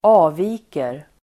Uttal: [²'a:vi:ker]